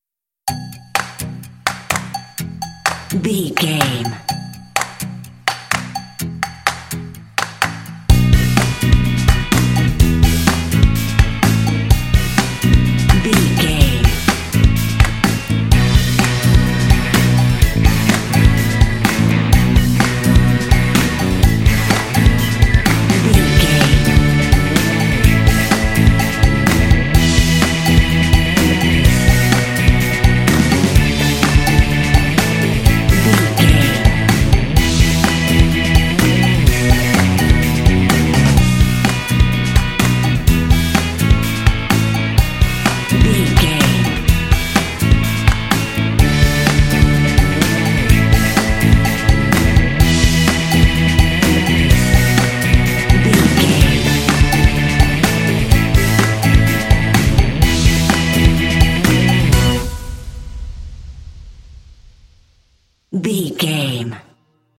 This fun and lighthearted track features a funky organ.
Uplifting
Mixolydian
bouncy
electric guitar
drums
percussion
organ
bass guitar
rock
indie
blues